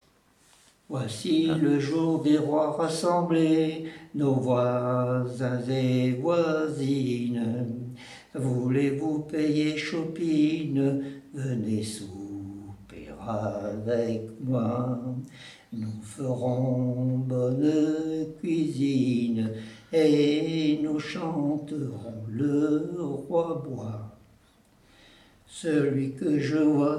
Note Chanson de la fête des bouviers
Enquête Enquête ethnologique sur les fêtes des bouviers et des laboureurs avec l'aide de Témonia
Catégorie Pièce musicale inédite